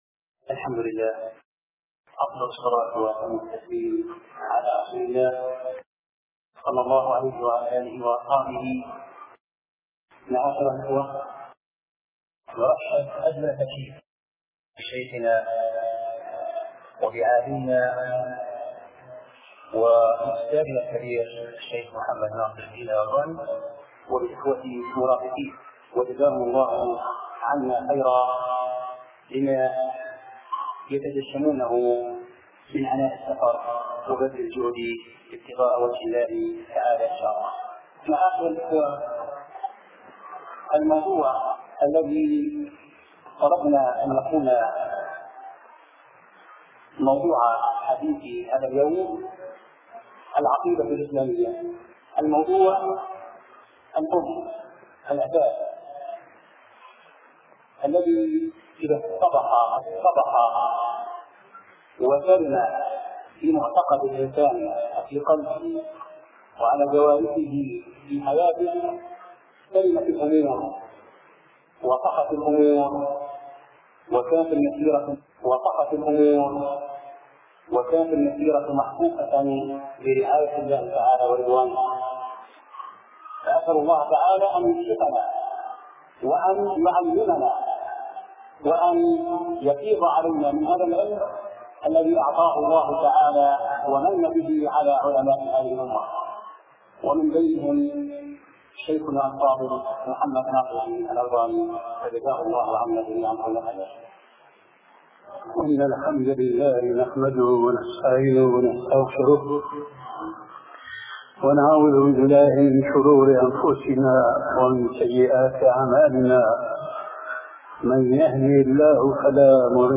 شبكة المعرفة الإسلامية | الدروس | ضرورية التوحيد |محمد ناصر الدين الالباني